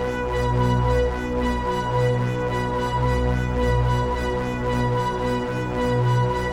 Index of /musicradar/dystopian-drone-samples/Tempo Loops/110bpm
DD_TempoDroneD_110-B.wav